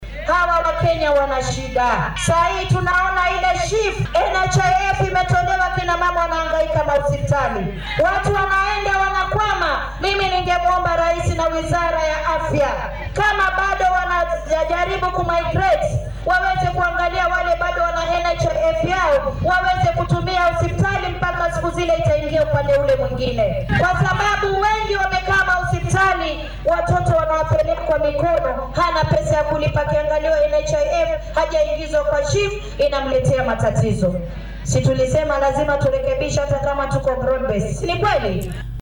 DHAGEYSO:Wakiilka haweenka ee Mombasa oo ka hadashay caymiska cusub ee caafimaadka